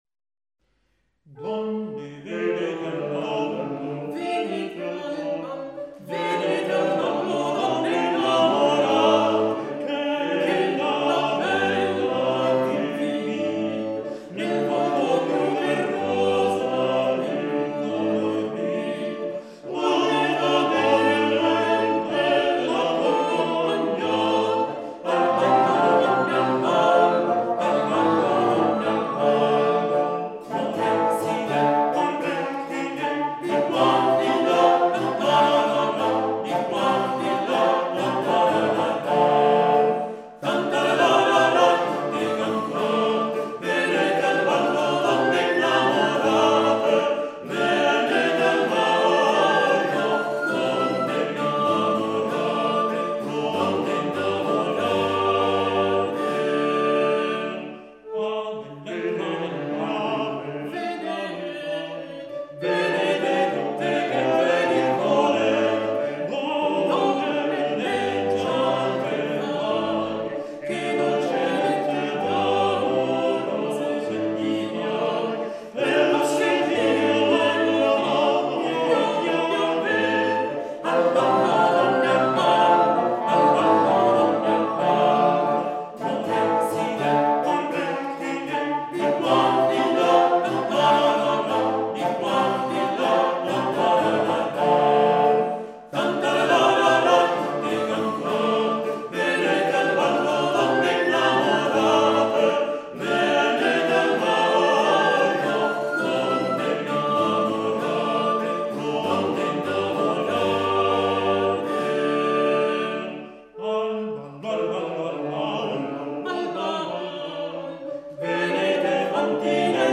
Registrazione "Donne, venete al ballo" - Villotta